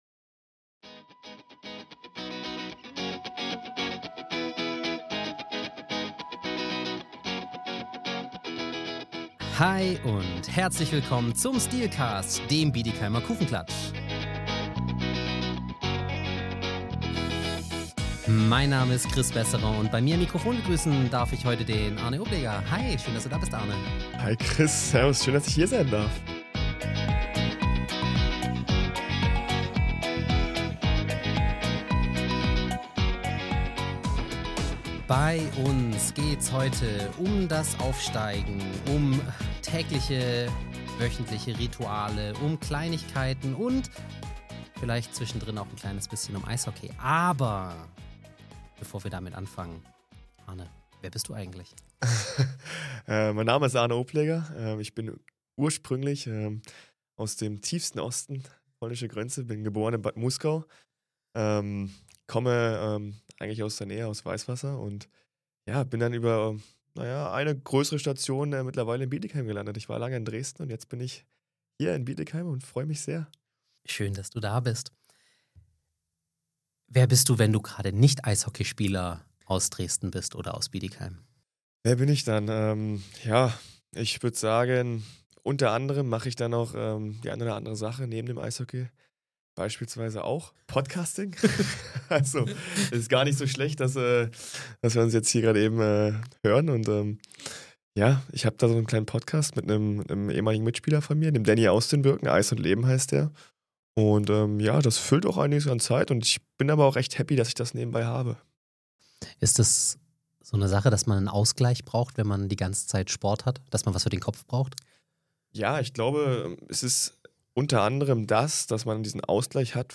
Ein Gespräch über Disziplin, Emotion und Haltung auf und neben dem Eis.